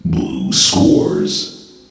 voc_blue_scores.ogg